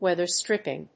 アクセントwéather strìpping